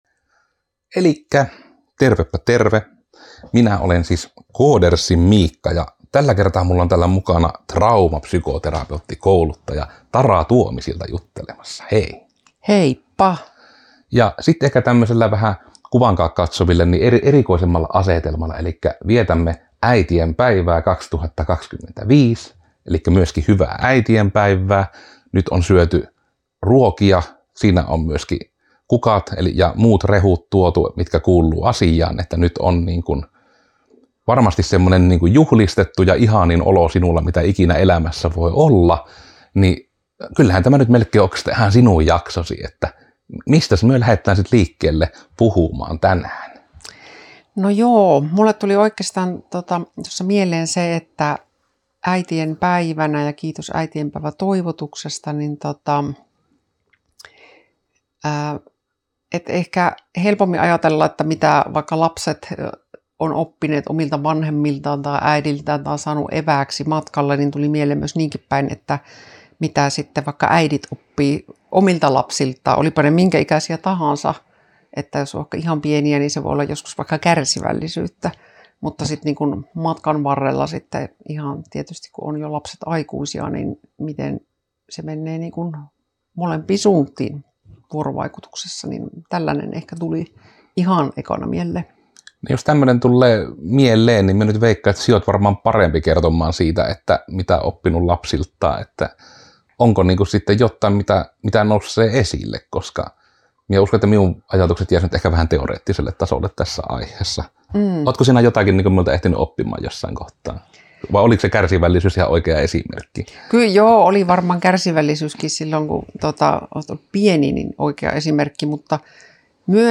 Tänä äitienpäiväisenä jaksona keskustellaan äiti ja poika, traumapsykoterapeutti ja insinööri eri opeista mitä voi tulla monialaisessakin yhteisössä ja miten paljon voi olla erilaisia oletuksia organisaation (tai suvun) sisällä.
MitäVattua on mukahauska podcast, jossa yleensä keskustellaan tekniikasta, it-alan pöhinäsanoista ja itketään maailman menoa, koska mikään ei ole koskaan hyvin.